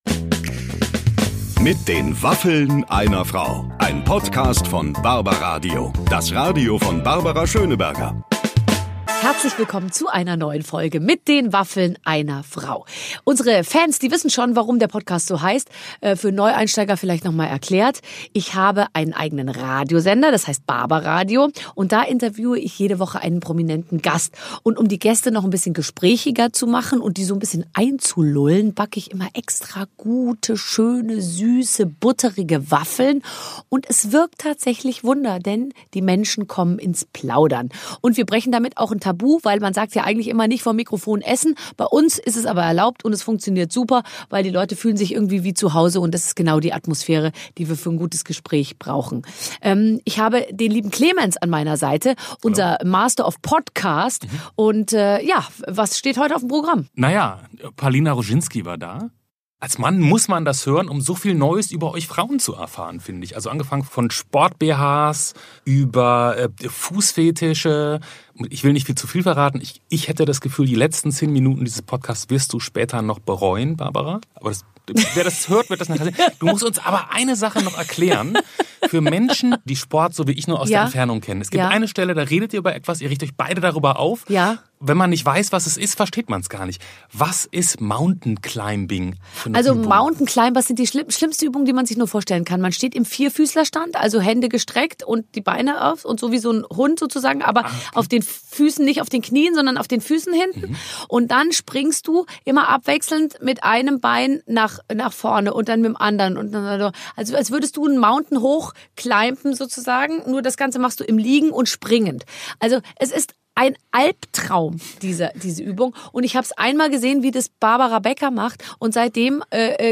Zwei Frauen ohne Berufsbezeichnung über unanständige Fan-Bilder, unschaffbare Sportübungen. Wenn Barbara Schöneberger auf Palina Rojinski trifft, gibt es viel zu besprechen.